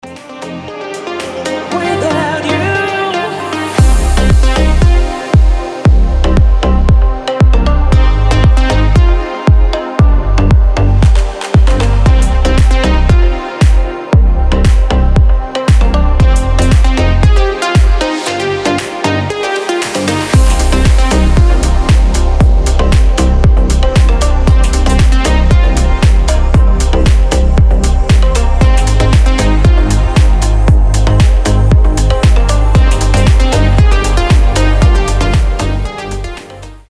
deep house
женский голос
Vocal House
Dark house
Стиль: deep house, dark house, vocal house